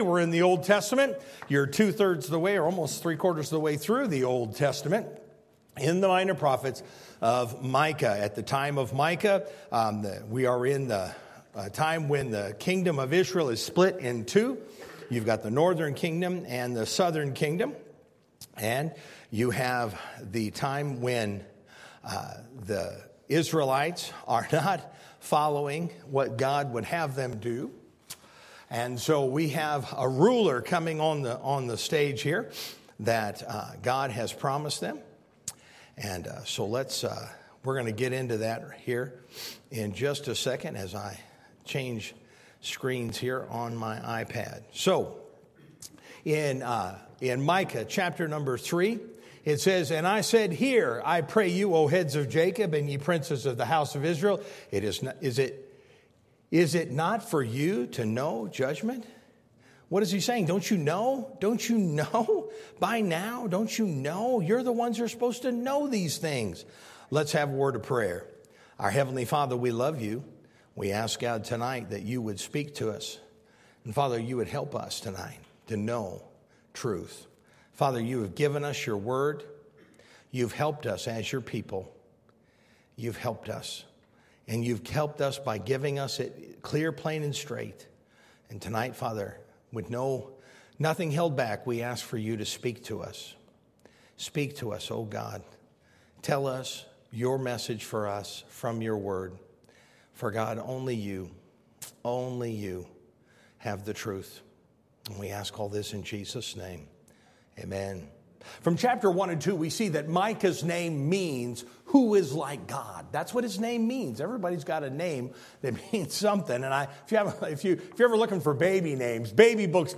Sermon Audio :: First Baptist Church of Kingstowne